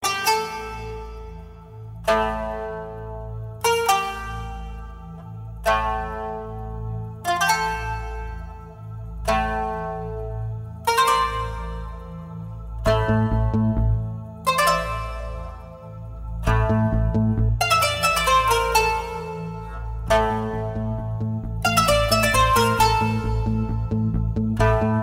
زنگ عربی